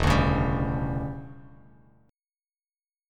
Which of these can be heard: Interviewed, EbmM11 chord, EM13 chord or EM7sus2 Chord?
EM13 chord